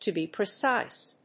アクセントto be precíse